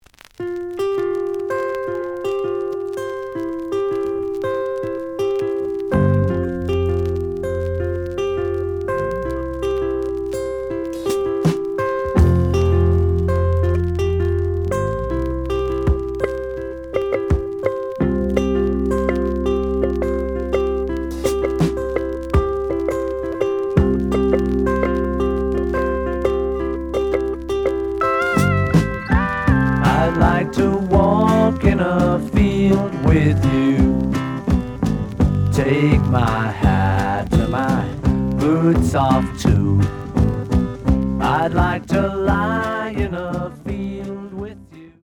The audio sample is recorded from the actual item.
●Genre: Rock / Pop
Slight noise on B side.